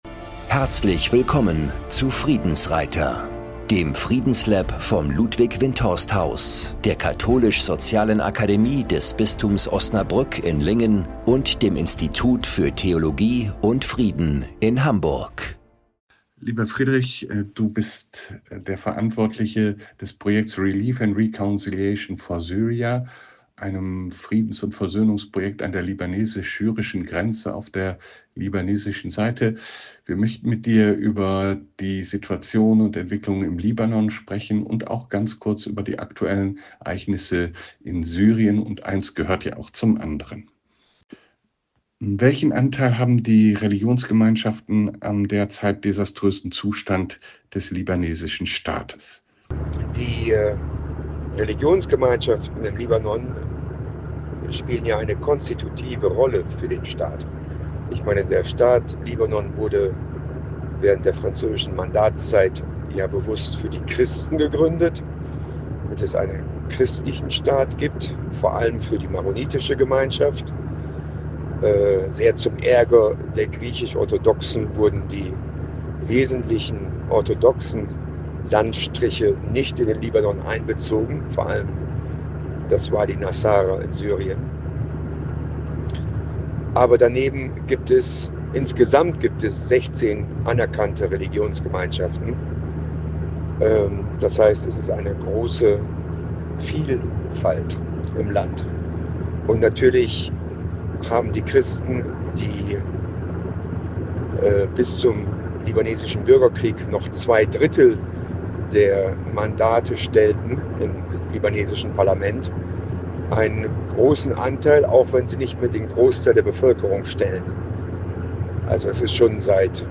Im per What´s App geführten Kompakt-Interview